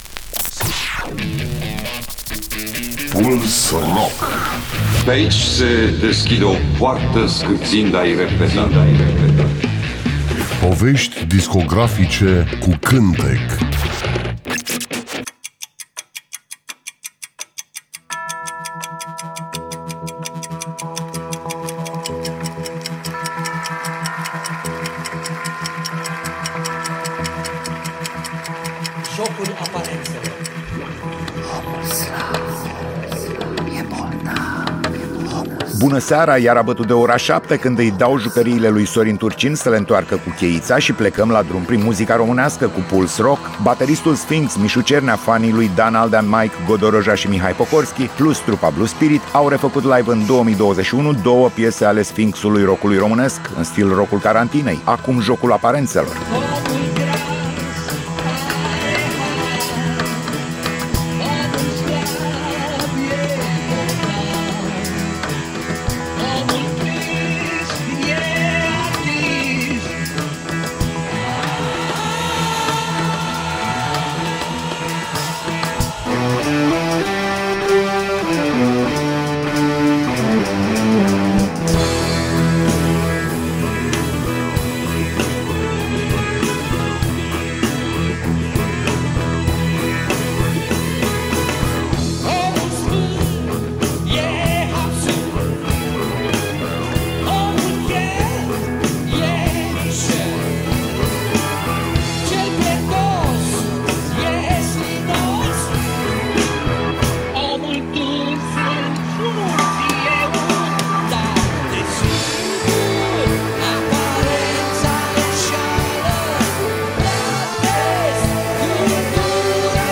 Emisiunea se numește Puls Rock și jonglează cu artiștii noștri rock, folk, uneori chiar jazz.